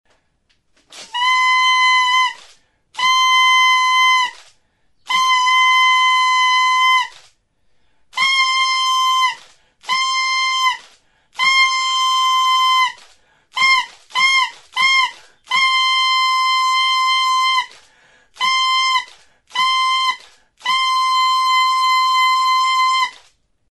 Aérophones -> Anches -> Simple battante (clarinette)
Enregistré avec cet instrument de musique.
Putz egiterakoan mihiak hotsa ematen du eta aldi berean paperezko suge koloreduna ziztu bizian luzatzen da.